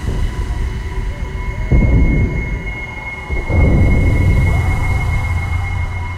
Alarm2_5.ogg